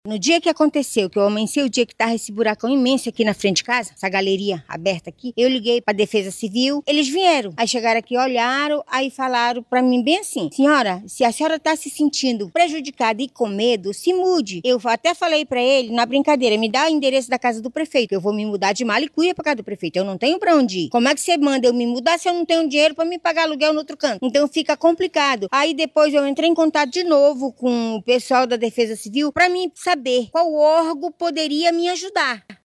Outra moradora relata que acionou a Defesa Civil do Município, que foi até o local, não interditou o imóvel e disse que se ela se sentisse prejudicada, deveria se mudar.